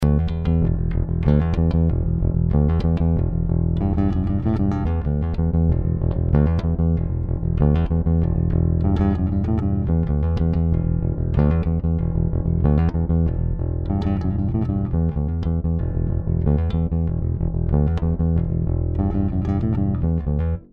Le positif : un look unique et le son de Maiden au bout des doigts.